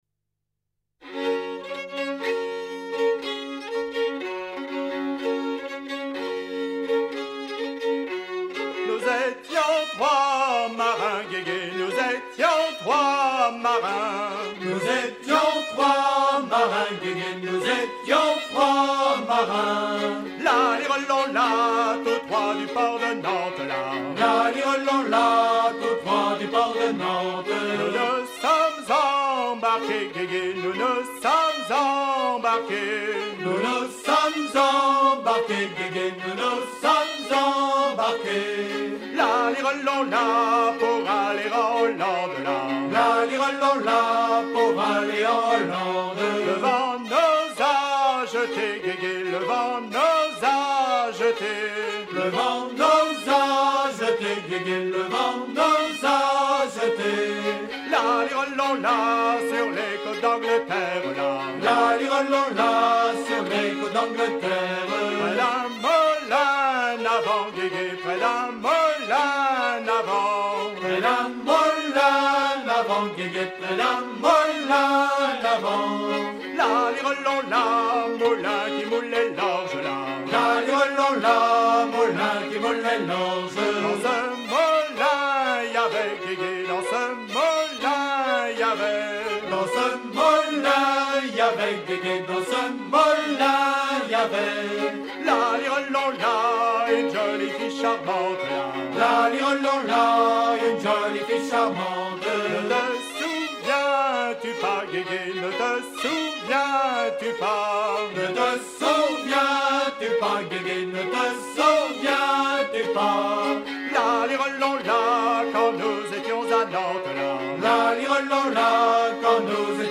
circonstance : maritimes
Genre laisse
Pièce musicale éditée